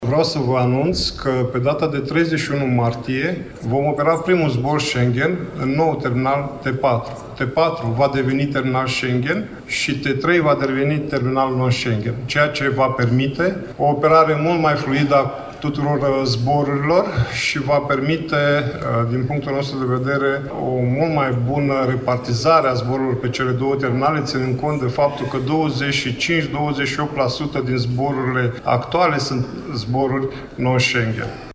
La Iași, a avut loc astăzi recepția lucrărilor la terminalul 4 al Aeroportului Internațional, investiţie care a fost finalizată la sfârşitul lunii decembrie 2023.